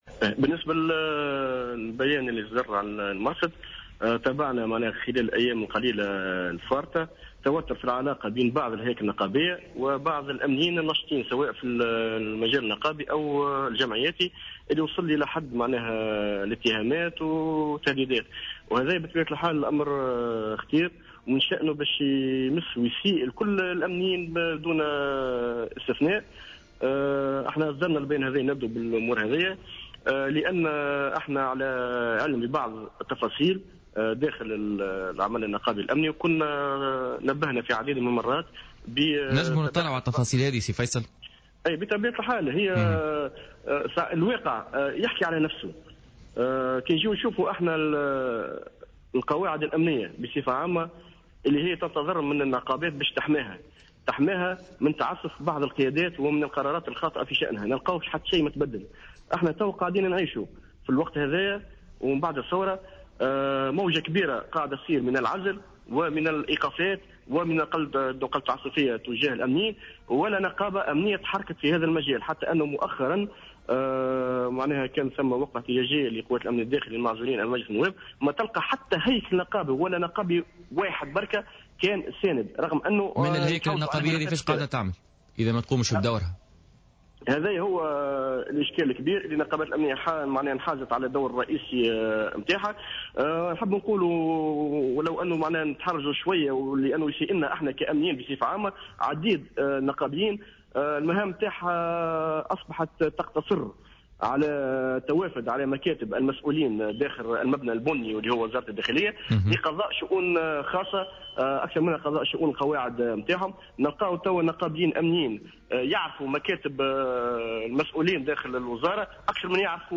في تصريح للجوهرة أف أم اليوم خلال حصة بوليتيكا